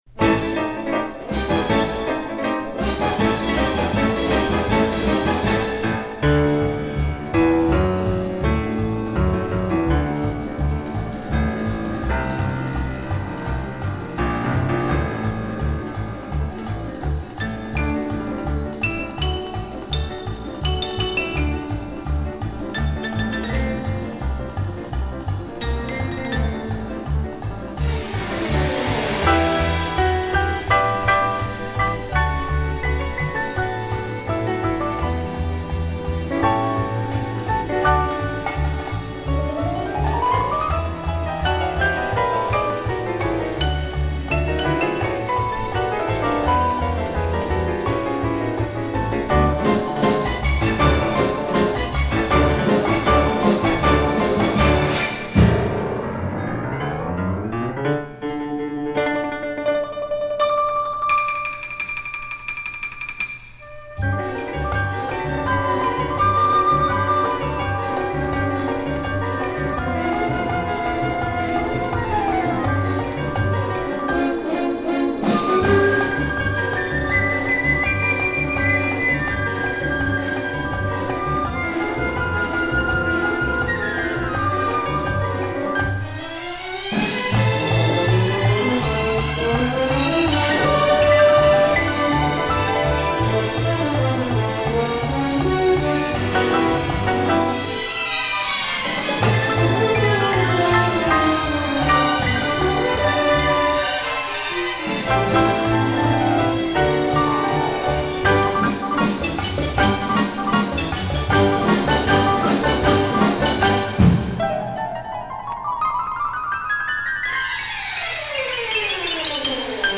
A raucous and inspiring